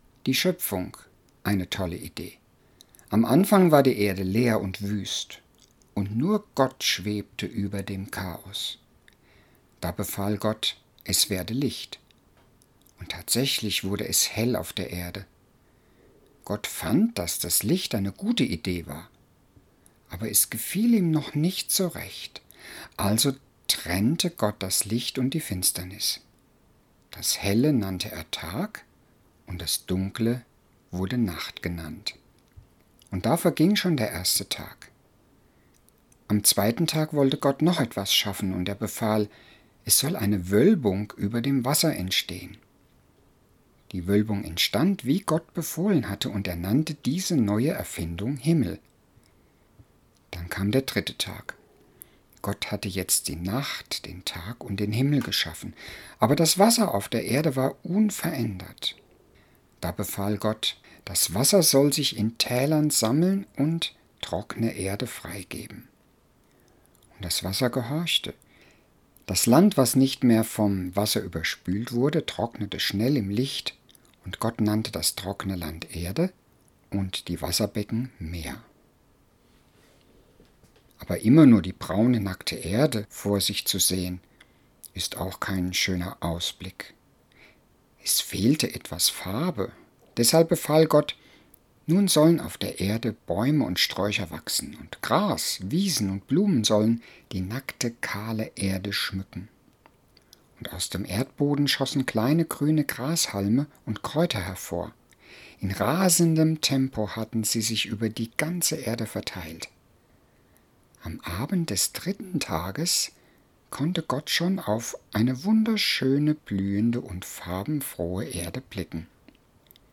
Hörgeschichte nach